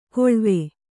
♪ koḷve